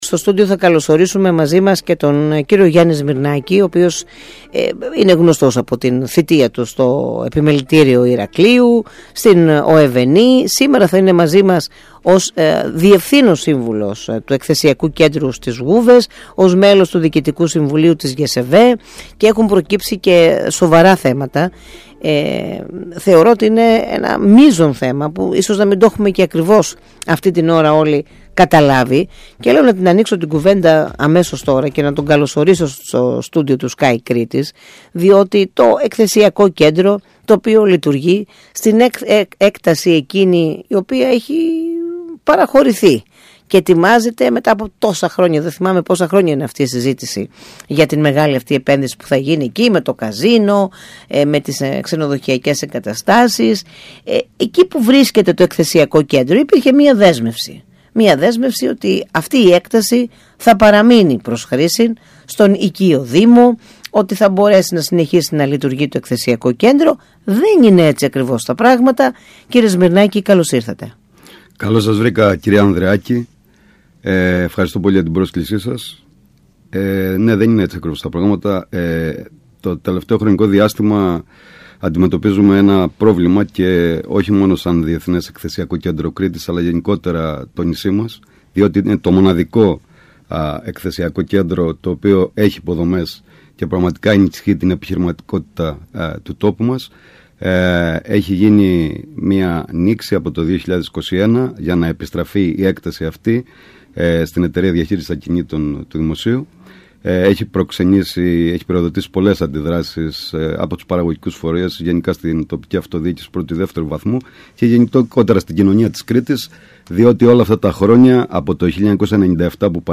Μιλώντας στον ΣΚΑΪ Κρήτης 92.1